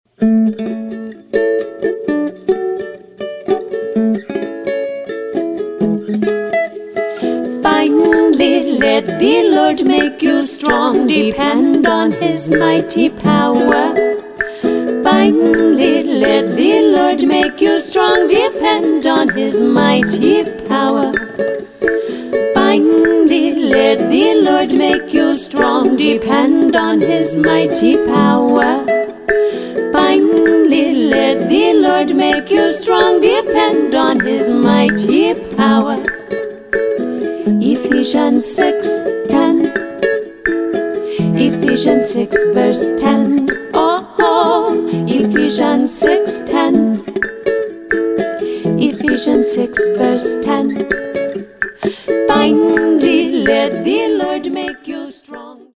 eclectic, contemporary Bible verse songs